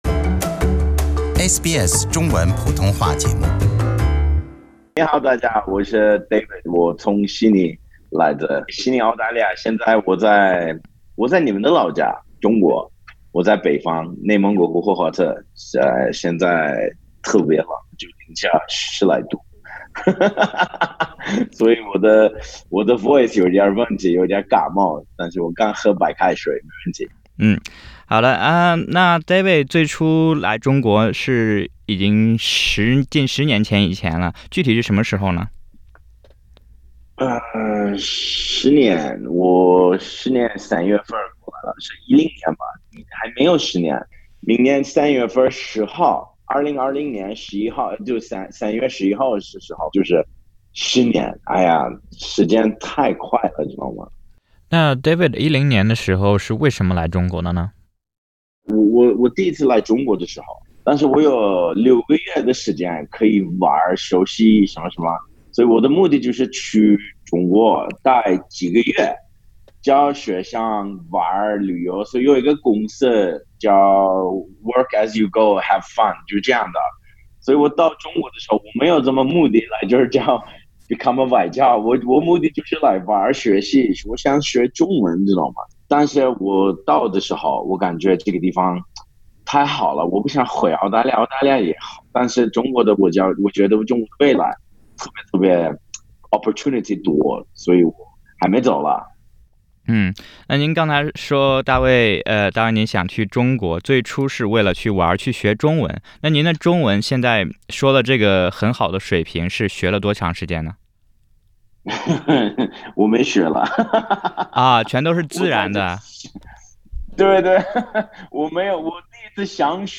完整采访请点击页面上方音频收听。